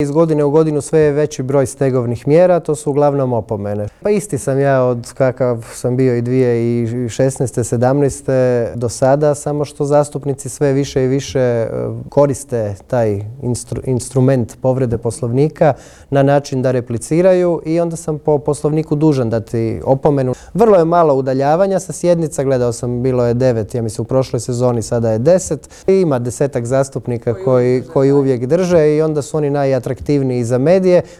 Otkrio nam je u Intervjuu tjedna Media servisa uoči održavanja sjednice Odbora za gospodarstvo upravo na temu prodaje plina višestruko ispod tržišne cijene.